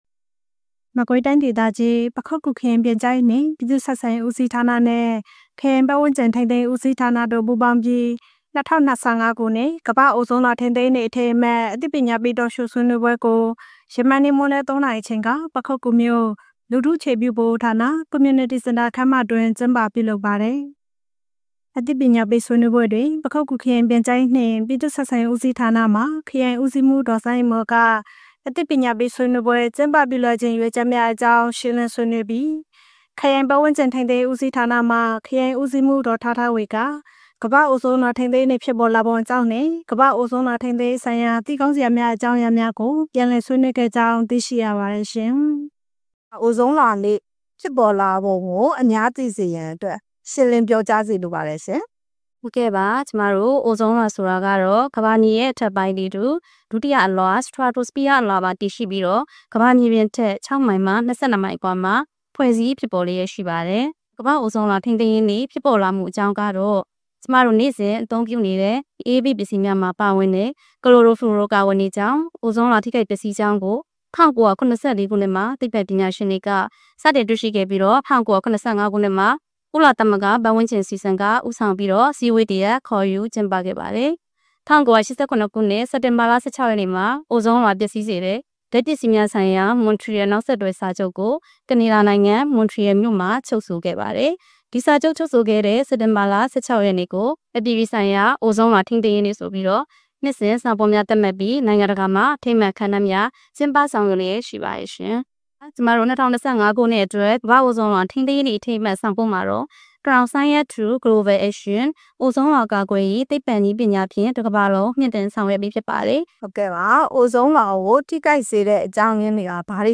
ပခုက္ကူမြို့၌ ၂၀၂၅ခုနှစ် ကမ္ဘာ့အိုဇုန်လွှာထိန်းသိမ်းရေးနေ့အထိမ်းအမှတ်အသိပညာပေး(Talk Show) ပွဲကျင်းပ ပခုက္ကူ စက်တင်ဘာ ၁၆